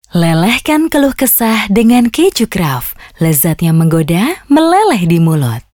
Voice Samples
female